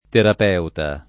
terap$uta] s. m. e f.; pl. m. ‑ti — anche terapista [terap&Sta]; pl. m. -sti — la 1a forma nel senso, a volte enfatico, di «medico (o sim.) abile nella cura delle malattie»; terapista, invece, nel sign. di «tecnico specializzato in una particolare pratica terapeutica»; inoltre, tutt’e due come forme abbreviate per psicoterapeuta e, risp., psicoterapista; e solo terapeuta nell’accez.